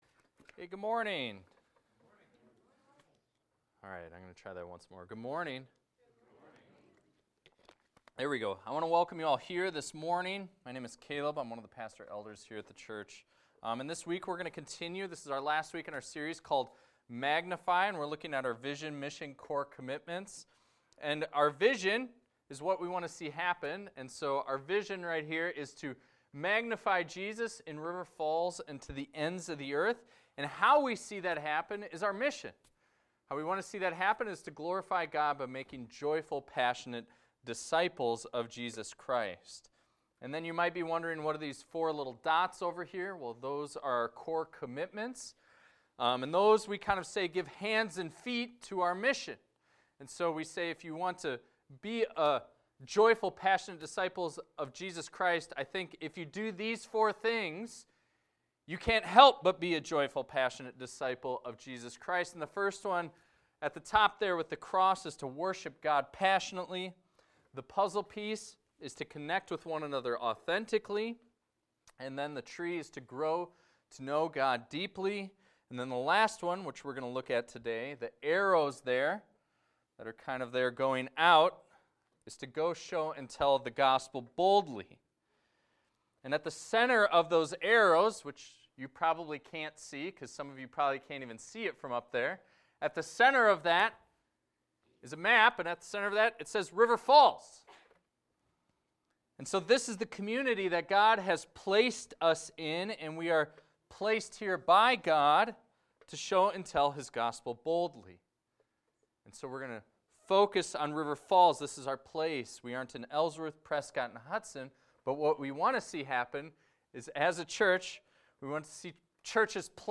This is a recording of a sermon titled, "We Are All to Go."